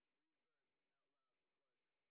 sp04_train_snr10.wav